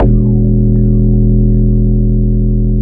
U F O BASS-L.wav